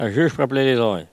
Elle crie pour appeler les oies